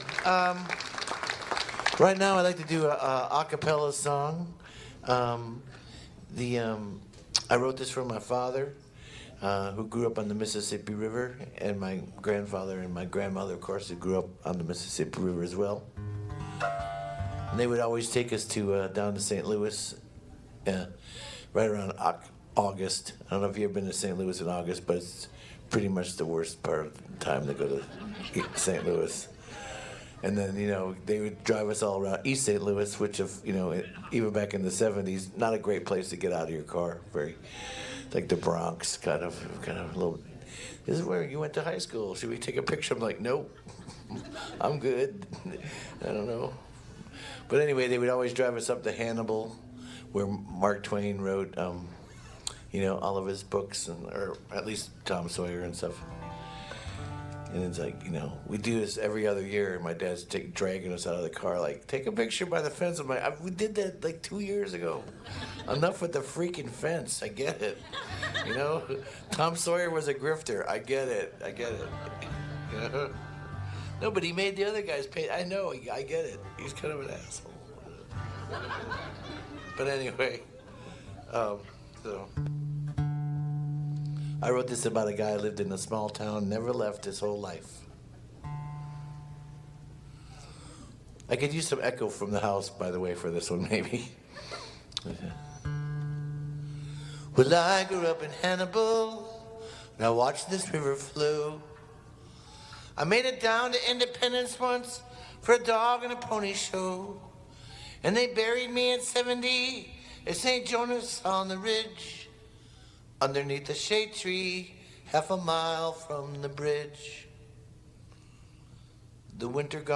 (captured from the live stream)